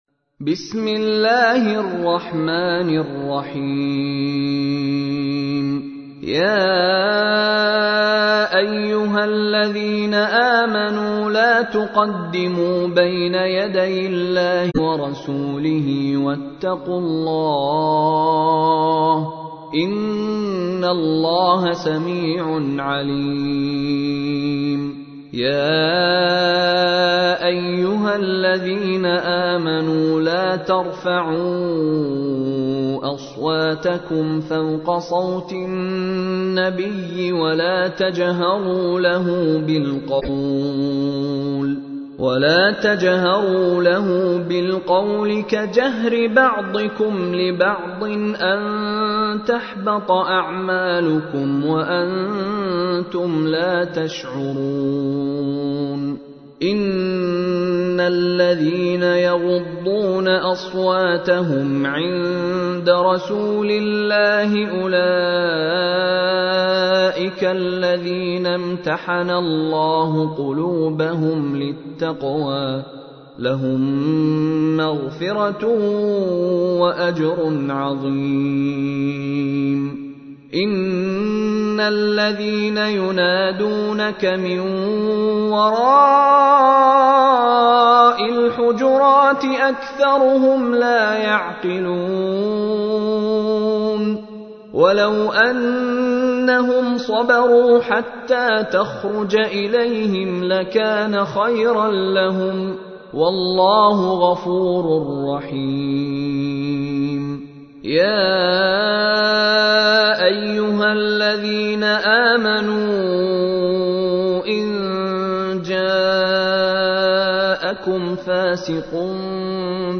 تحميل : 49. سورة الحجرات / القارئ مشاري راشد العفاسي / القرآن الكريم / موقع يا حسين